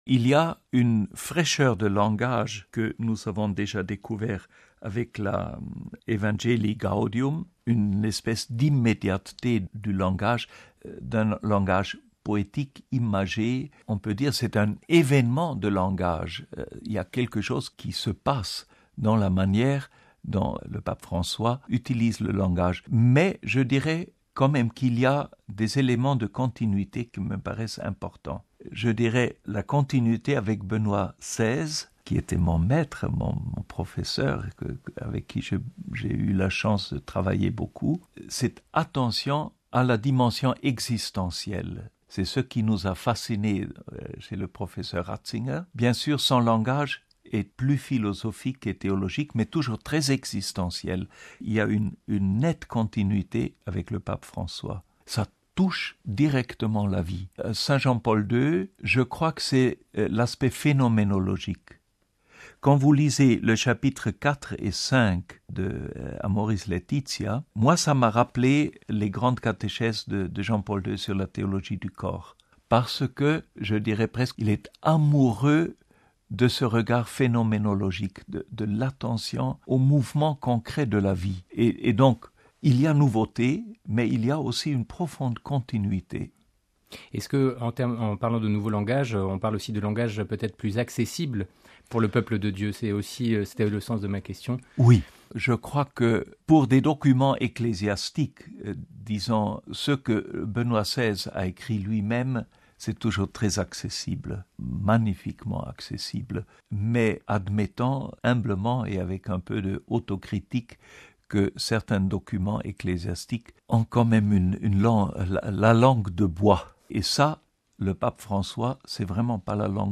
(RV) Entretien - Amoris Laetitia, "La Joie de l'amour", l'exhortation apostolique du Pape François sur la famille, a été rendue publique ce vendredi midi, 8 avril 2016. Ce texte fait suite aux deux Synodes sur la famille, de 2014 et 2015.